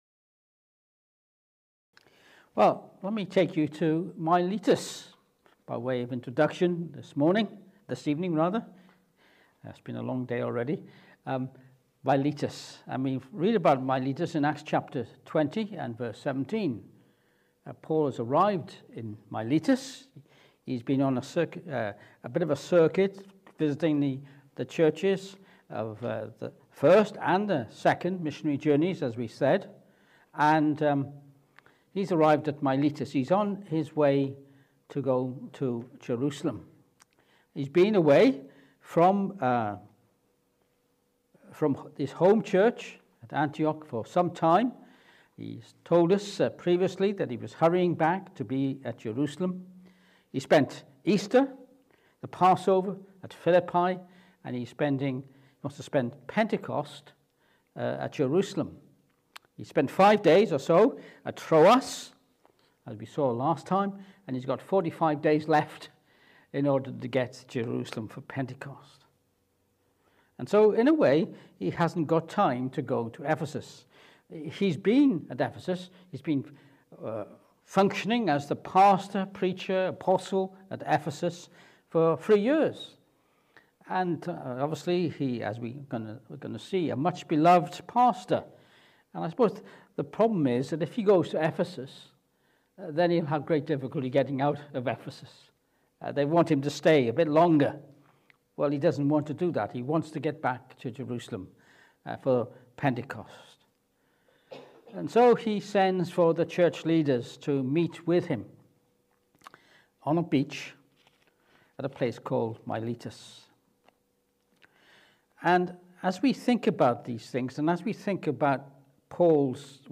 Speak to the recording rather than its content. Acts 20:17-38 Service Type: Evening Service This evening we will consider the Apostle Paul as the church pastor.